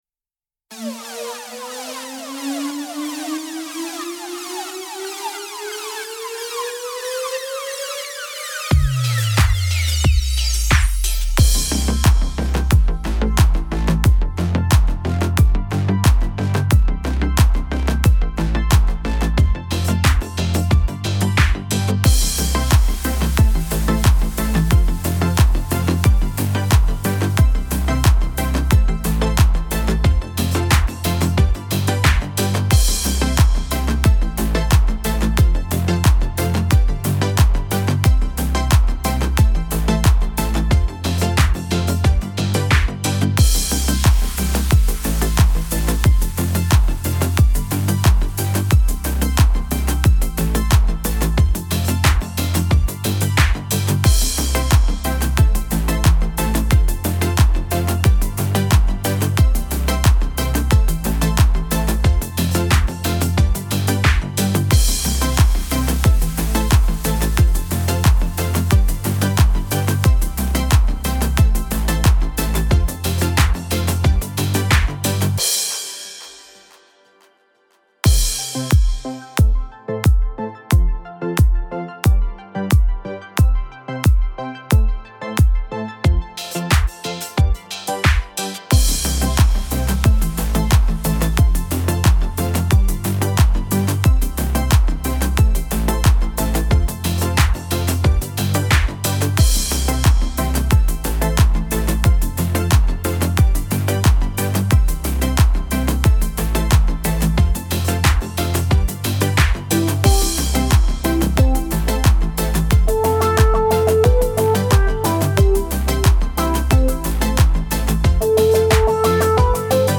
• Категория: Детские песни
Слушать минус
караоке
минусовка